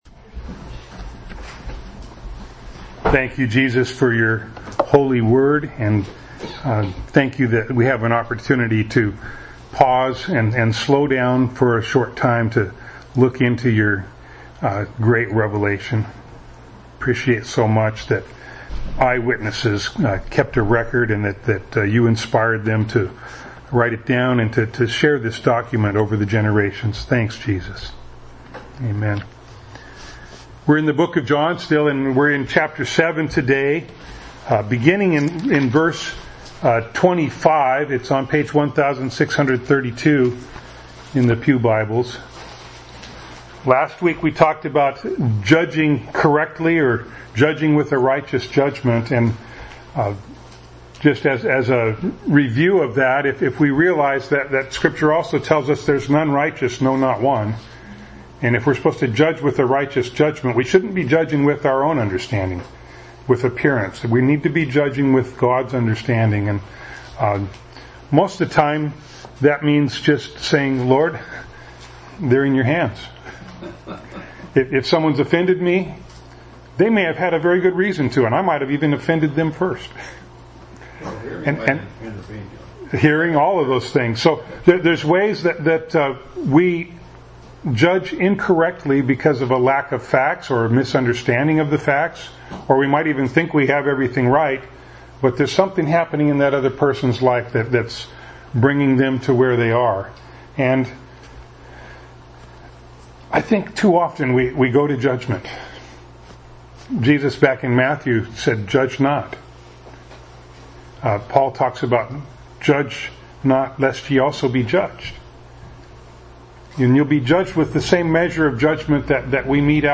John 7:25-52 Service Type: Sunday Morning Bible Text